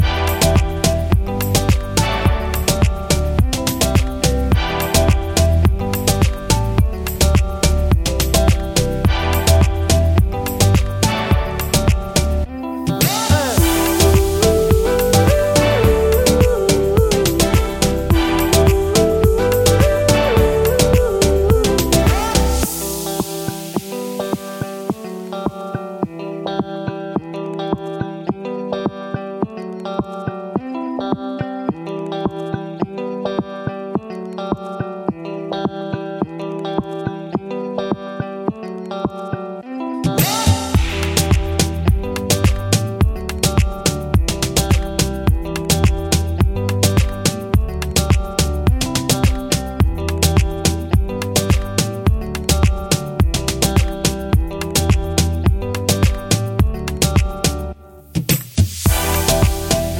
no Backing Vocals Pop (2020s) 3:05 Buy £1.50